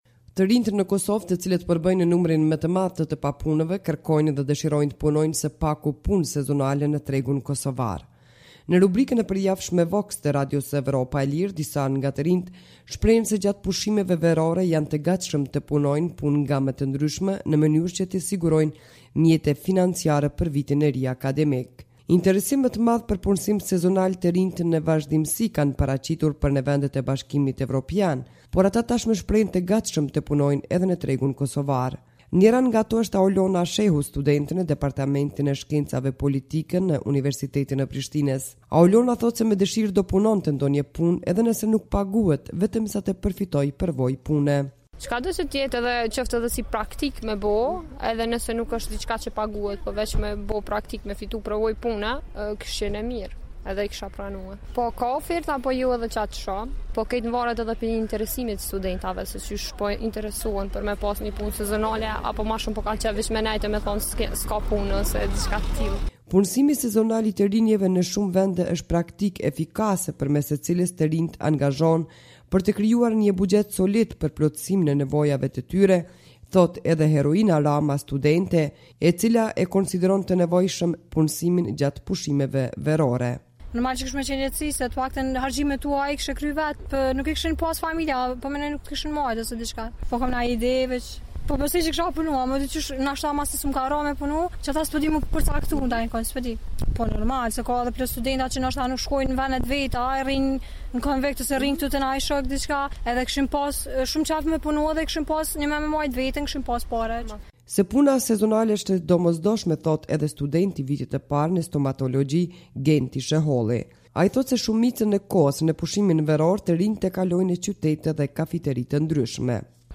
Në rubrikën e përjavshme “VOX” të Radios Evropa e Lirë, disa nga të rinjtë e anketuar thonë se gjatë pushimeve verore, janë të gatshëm të punojnë punë nga më të ndryshmet, në mënyrë që të sigurojnë mjete financiare për vitin e ri akademik.